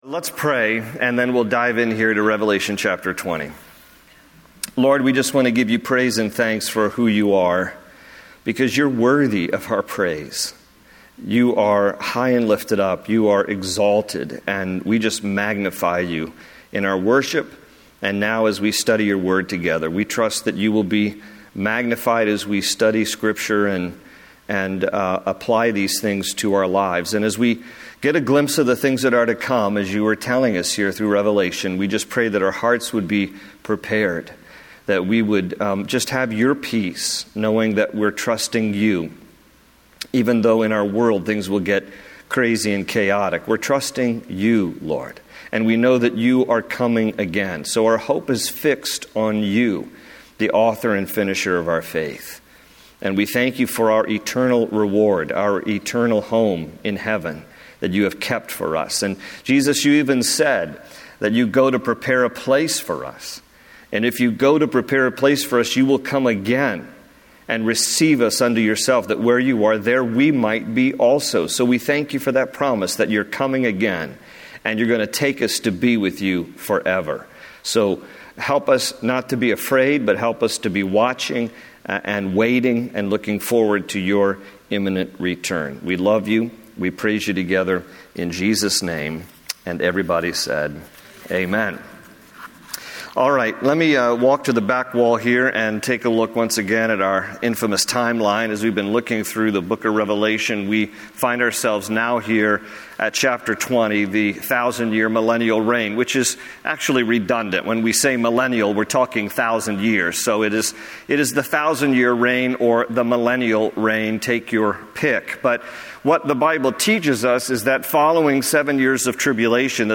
Download Full Teaching